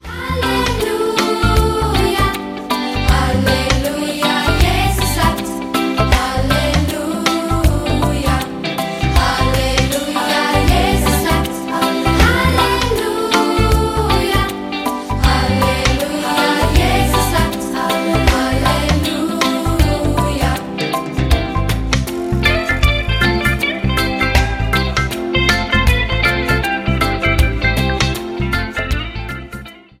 neue und alte Dialektsongs für Kinder